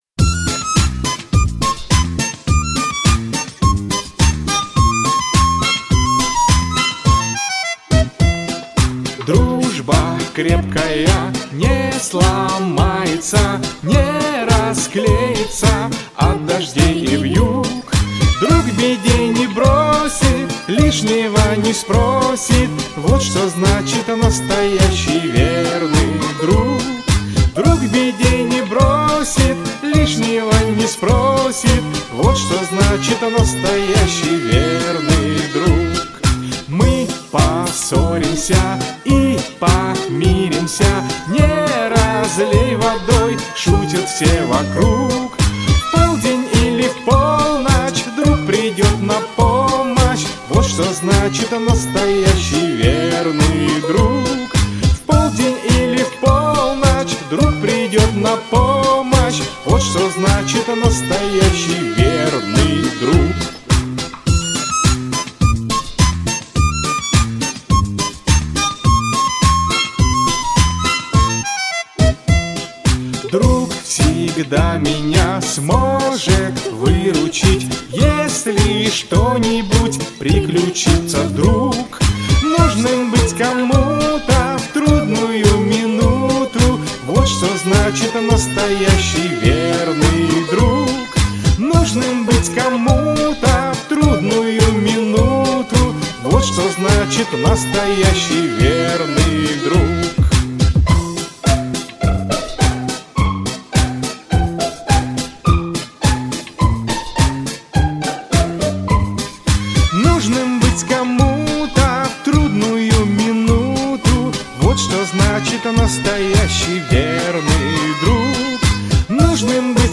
• Качество: Хорошее